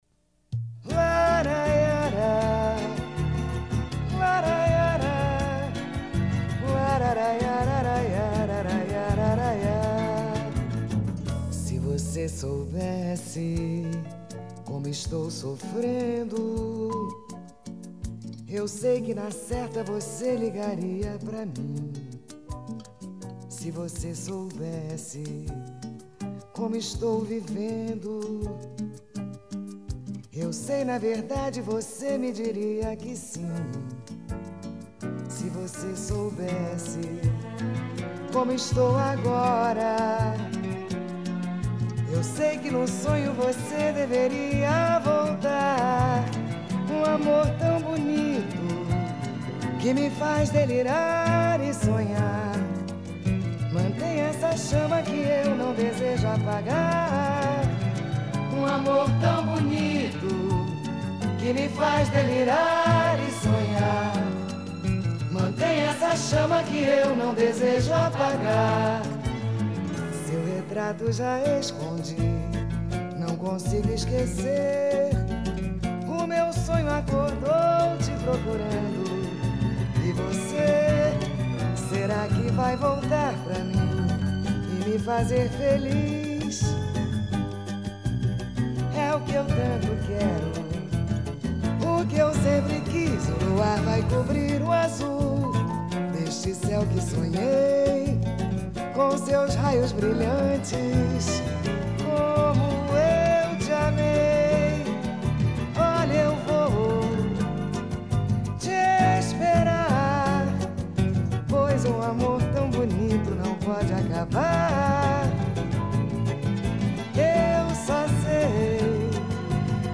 Samba E Pagode